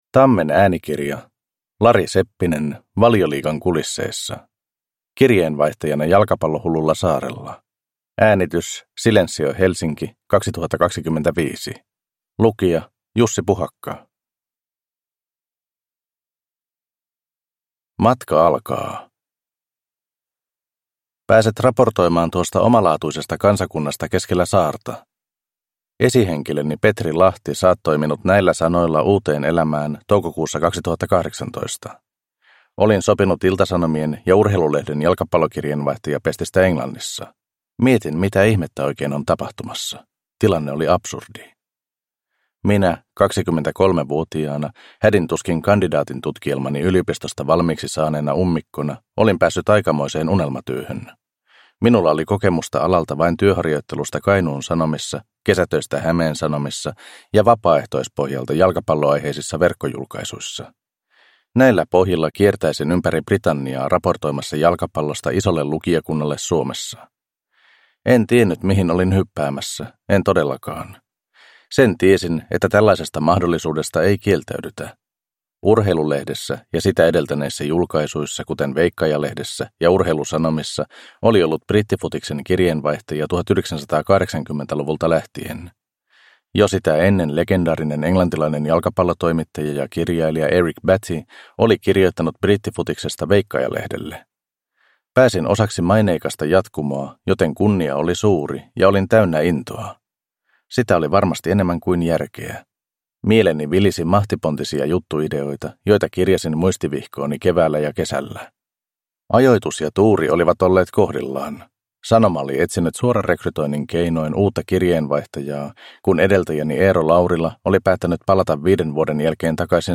Valioliigan kulisseissa – Ljudbok